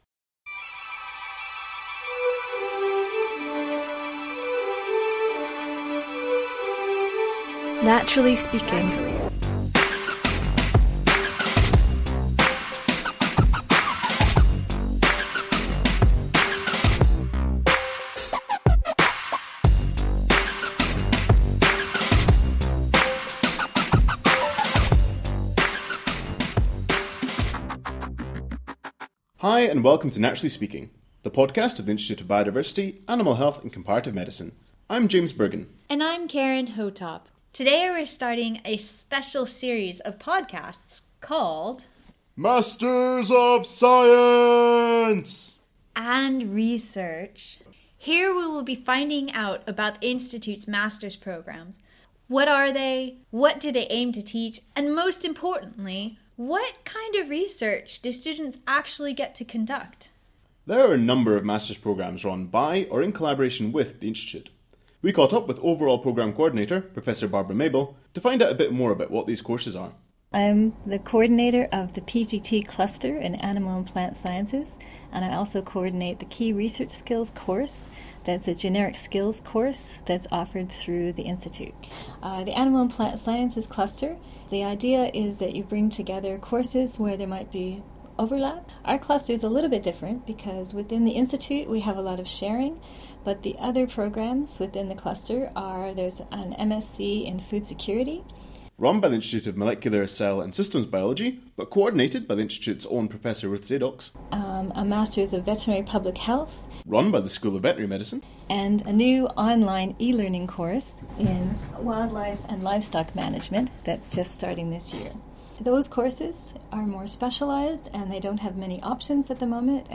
Get ready for a diverse crew, with accents from Scotland, Tanzania and beyond, and projects ranging from comparative amphibian behaviour to modelling disease vaccinations in tigers!